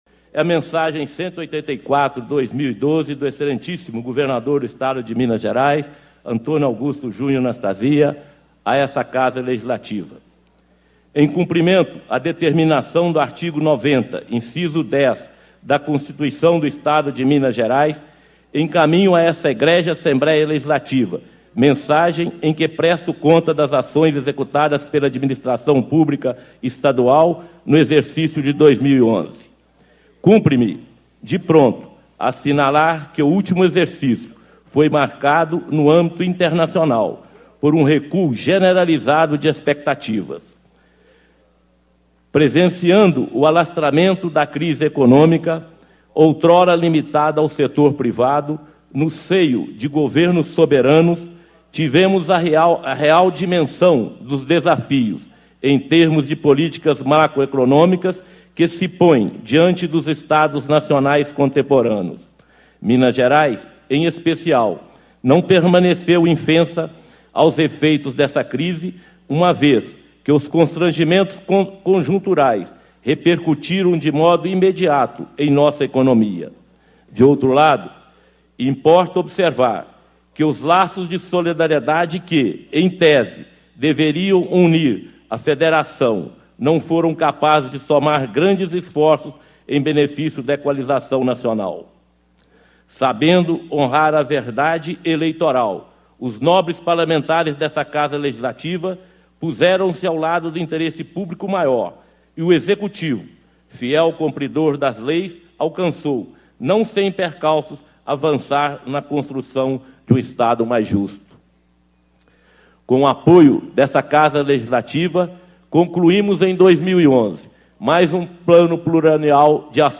Instalação da 2ª Sessão Legislativa da 17ª Legislatura - Reunião Solene